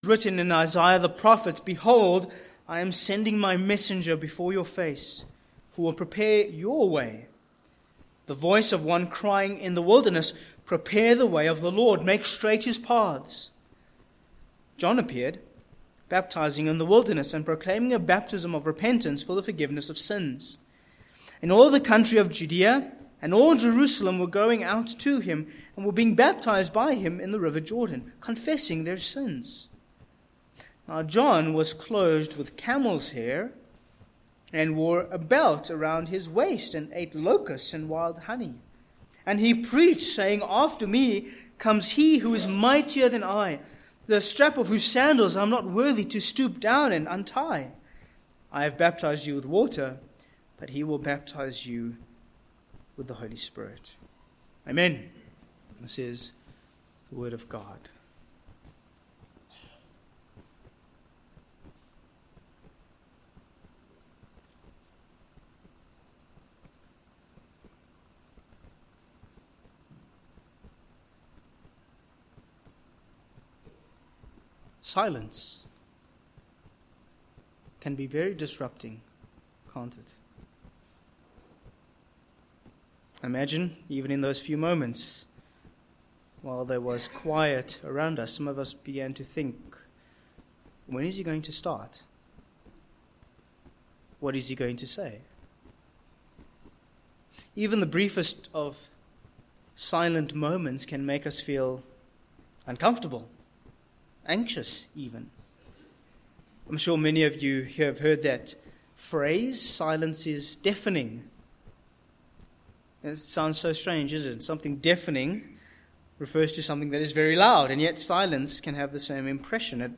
Sermon points: 1. A Careful Introduction v1
Service Type: Morning